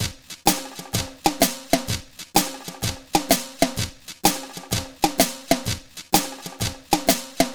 Swingerz Drumz Dry.wav